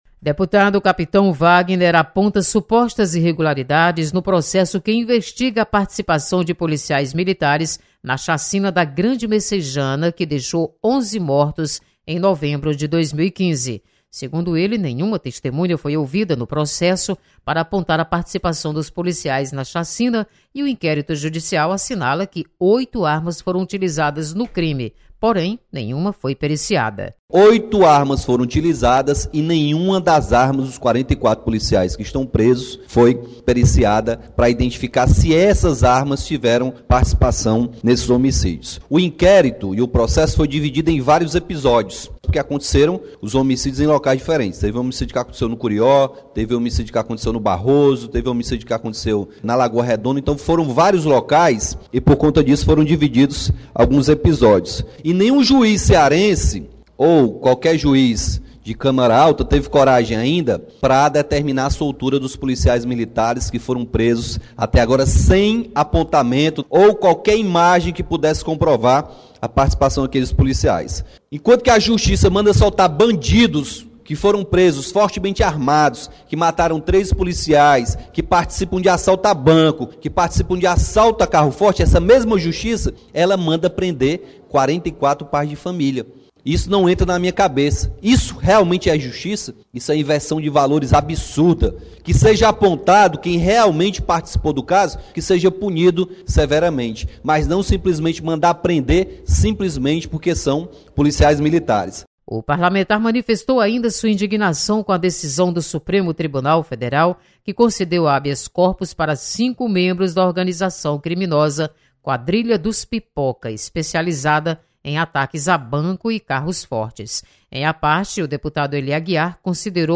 Você está aqui: Início Comunicação Rádio FM Assembleia Notícias Policiais